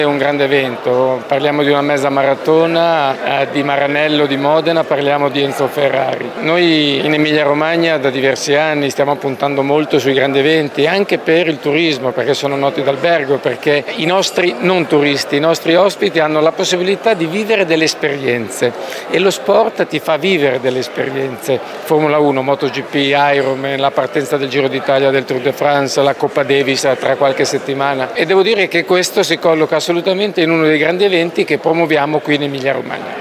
Il Presidente dell’APT Servizi Regione Emilia-Romagna Davide Cassani: